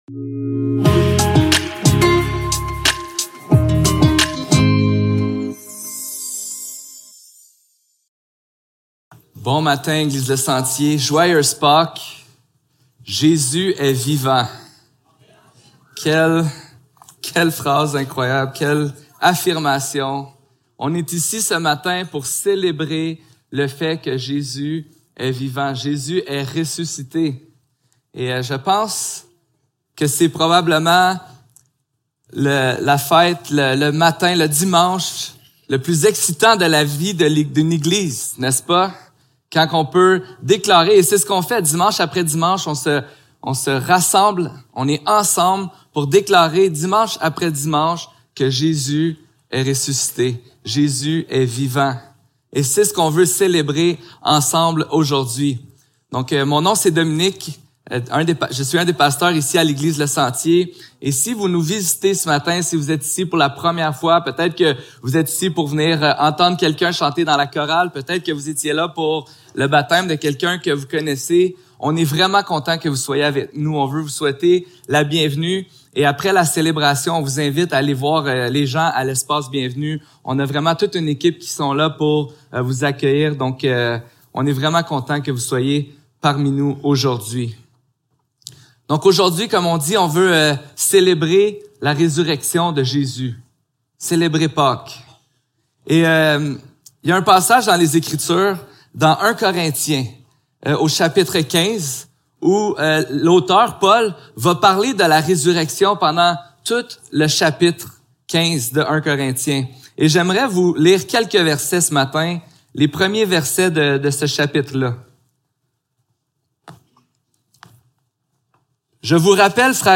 1 Corinthiens 15 Service Type: Célébration dimanche matin Description